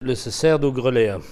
RADdO - Il se sert du crible à grains - Document n°228420 - Locution
Elle provient de Saint-Jean-de-Monts.